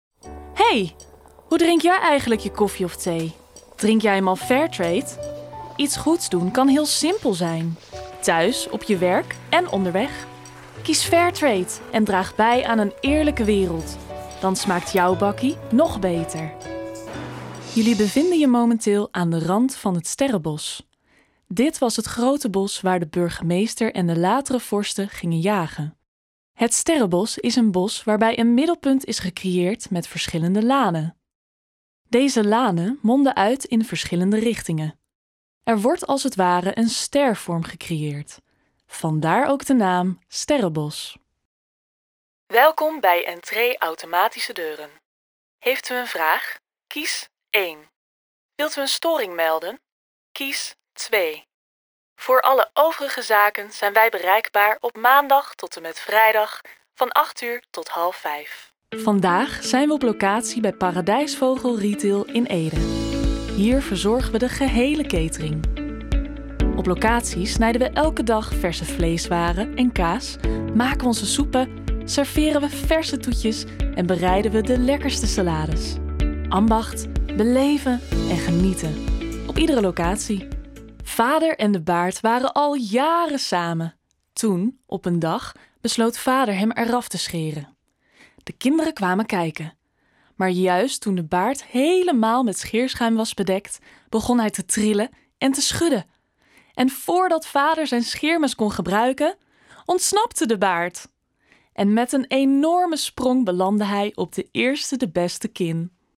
Jong, Natuurlijk, Speels, Toegankelijk, Vriendelijk
Audiogids
Equipped with a professional home studio, she delivers high-quality recordings with a fast turnaround, ensuring both efficiency and excellence in every project.